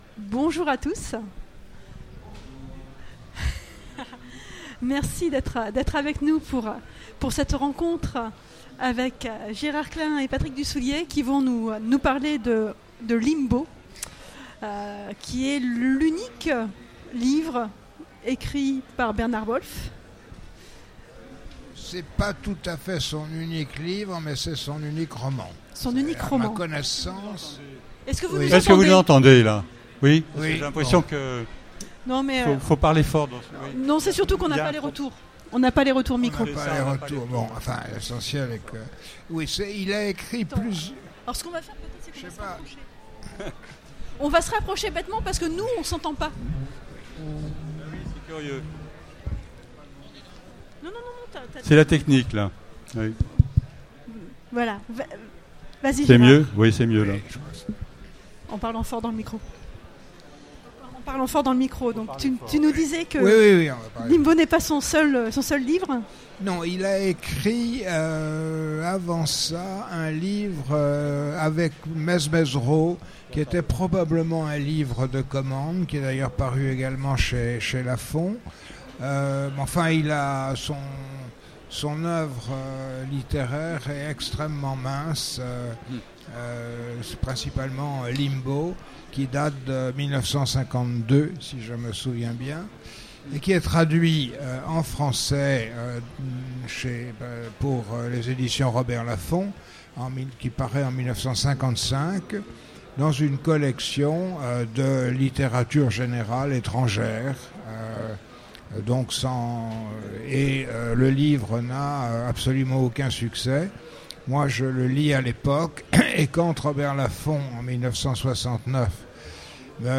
Utopiales 2016 : Conférence Limbo de Bernard Wolfe : une nouvelle traduction